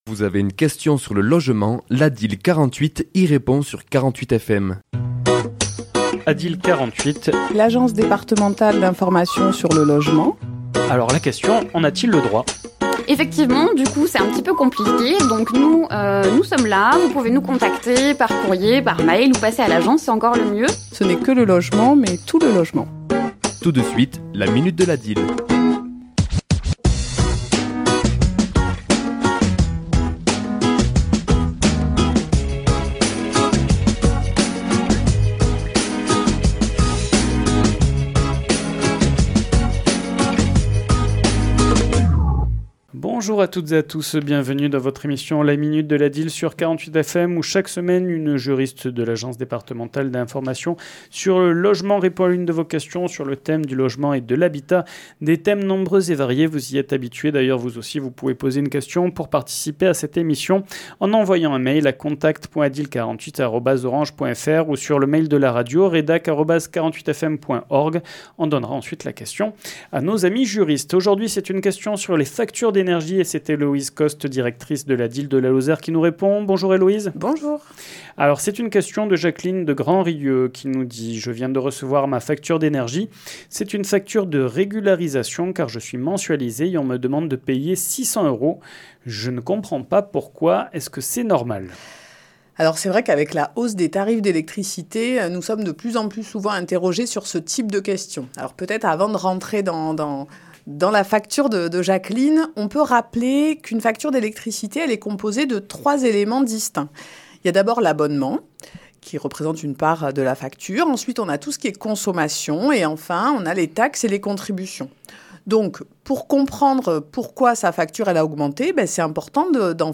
Chronique diffusée le mardi 05 novembre à 11h et 17h10